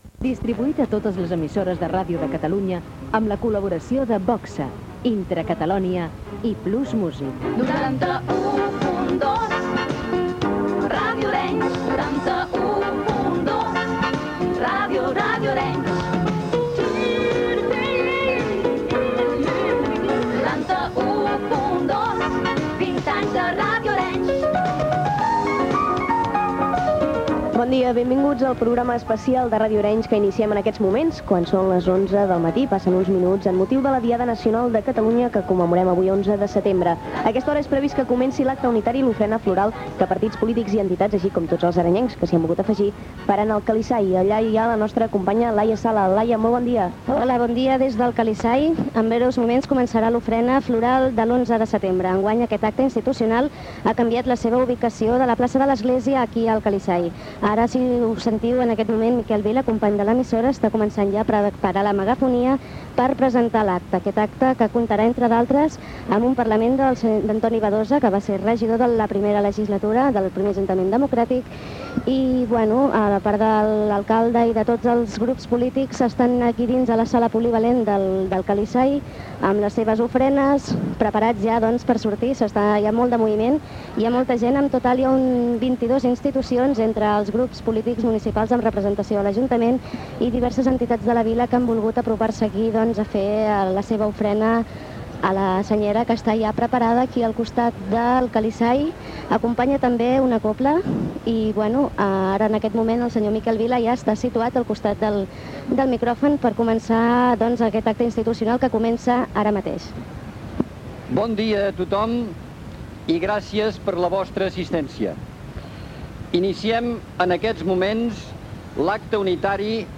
Indicatiu, programa especial de la Diada Nacional de l'11 de setembre per celebrar els 20 anys d'ajuntaments democràtics.
Informatiu
FM